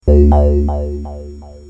SONS ET SAMPLES DU SYNTHÉTISEUR OBERHEIM MATRIX 1000